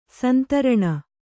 ♪ santaraṇa